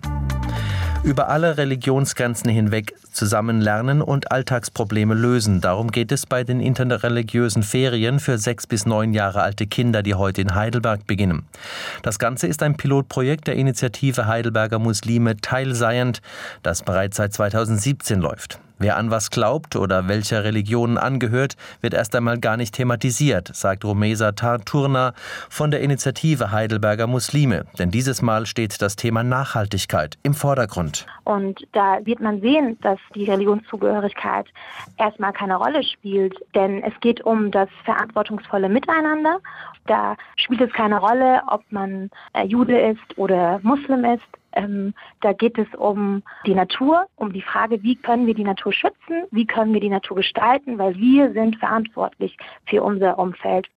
Radiobeiträge Regionalnachrichten SWR (03.04.2018):
Regionalnachrichten-SWR-3.4.-Teilseiend-1.wav